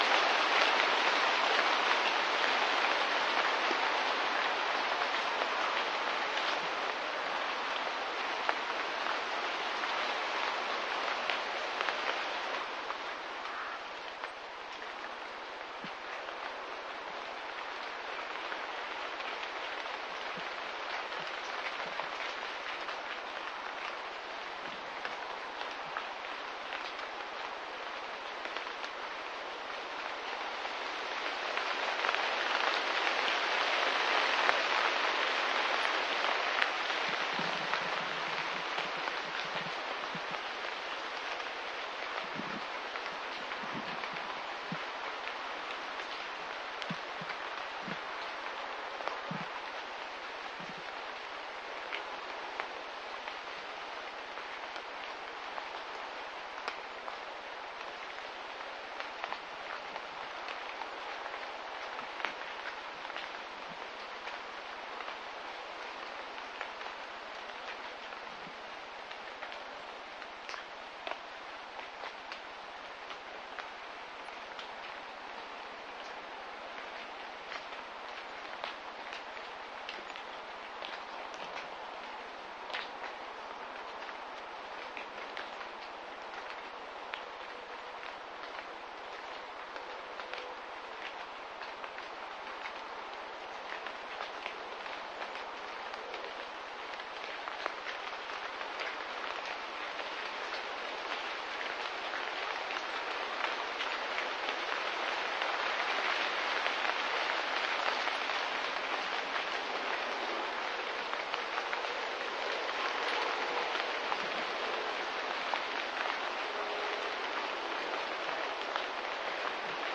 树上的雾与风
描述：这不是雨，但听起来像雨微风吹过，雾气和潮湿在树上沉淀。在我家上方的田野里，在雾和潮湿的环境中录制的所以听起来被放大了，而且 "空洞"。使用森海塞尔MKE300 "猎枪 "麦克风录制。
标签： 树木 叶子 现场记录 雨量
声道立体声